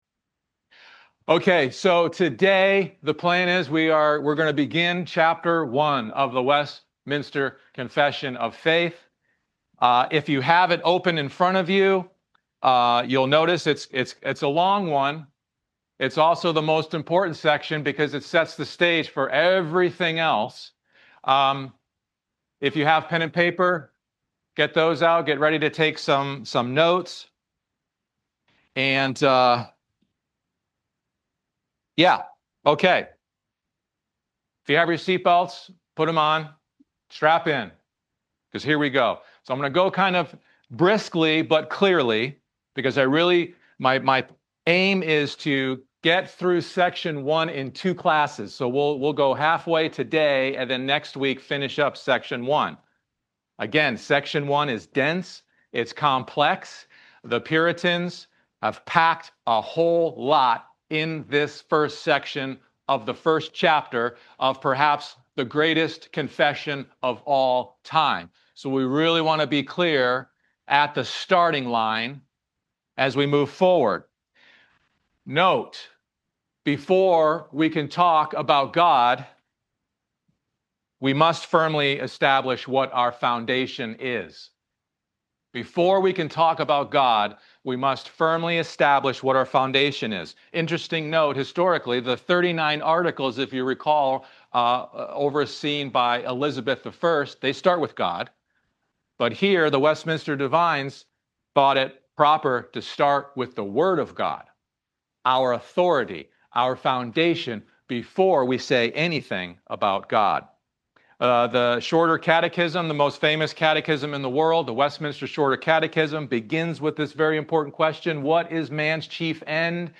A discussion of Section 1 of the Westminster Confession, on the canonicity and necessity of the Scriptures for salvation.